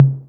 808 Md Tom.WAV